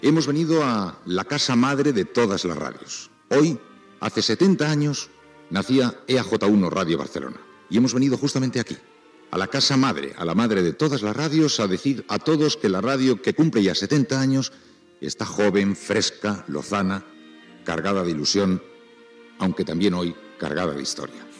Especial amb motiu del 70 aniversari de Ràdio Barcelona
Info-entreteniment